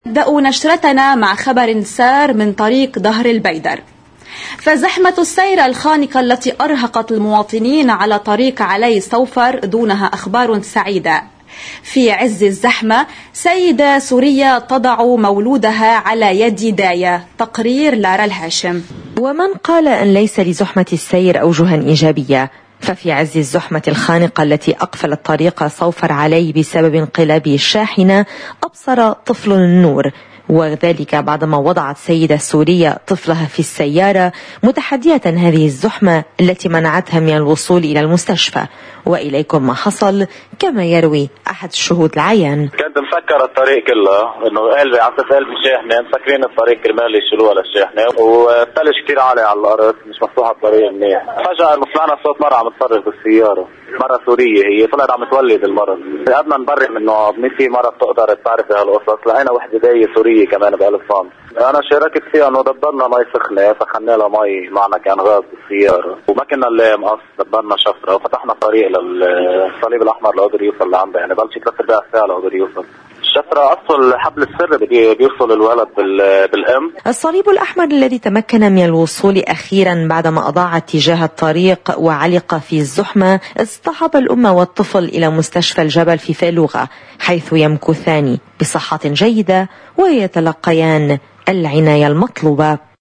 شاهد عيان يشارك بتأمين ماي سخنة كان معنا غاز بالسيارة، وما كنا نلاقي مقص، دبرنا شفرة..